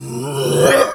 Animal_Impersonations
bear_pain_hurt_groan_08.wav